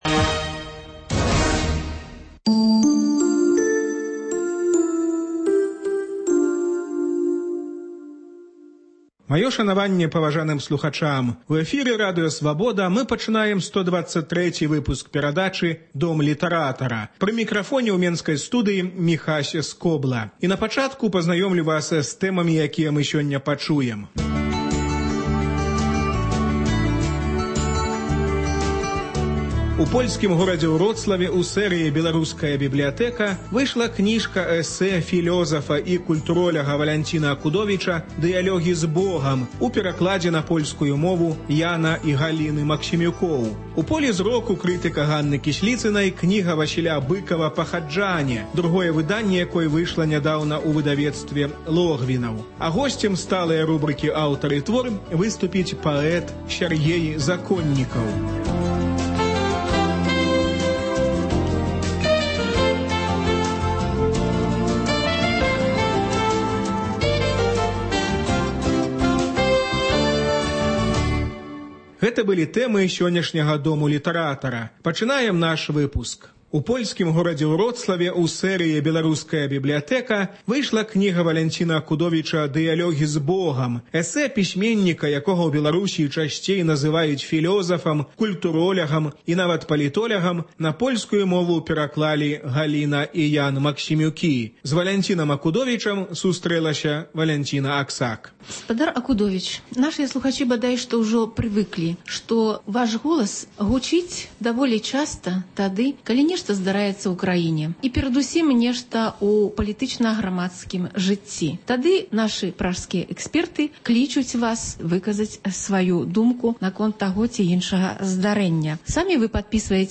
Літаратурны агляд з удзелам Валянціна Акудовіча